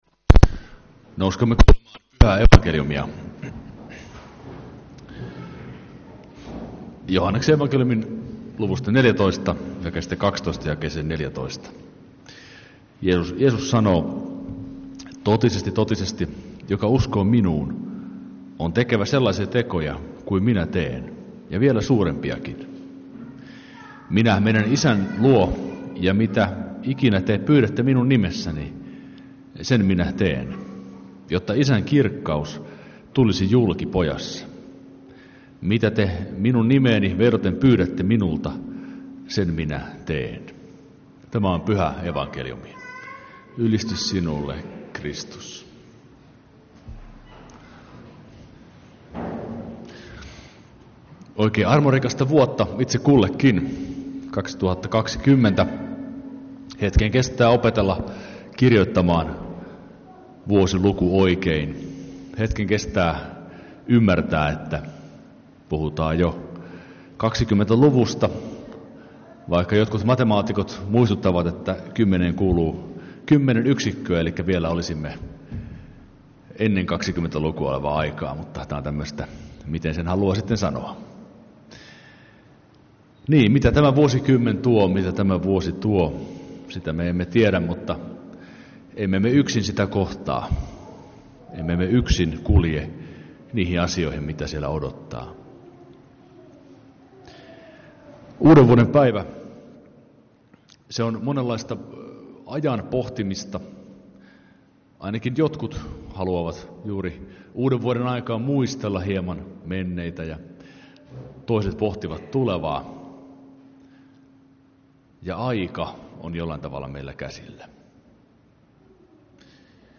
Kokoelmat: Lahden lutherin kirkon saarnat